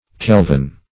Kelvin \Kel"vin\ (k[e^]l"v[i^]n), n. [from Lord Kelvin, English